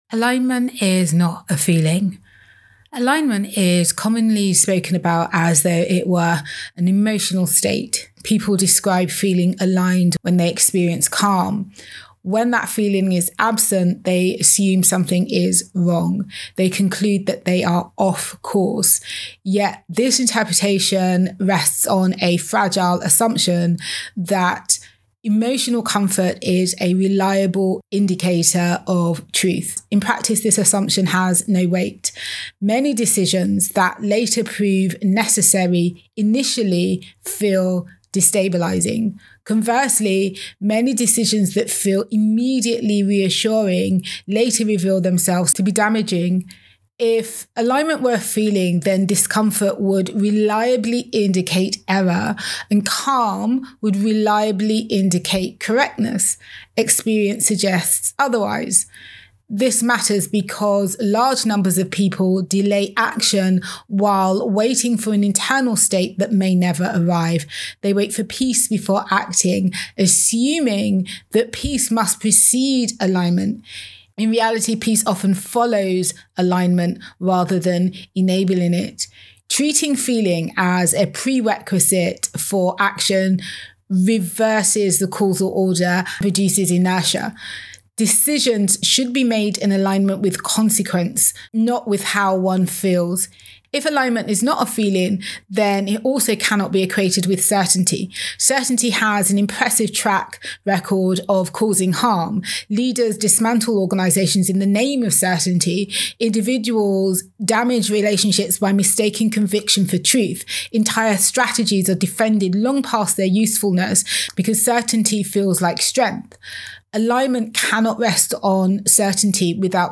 Audio Commentary
alignment_is_not_a_feeling_voice_recording.mp3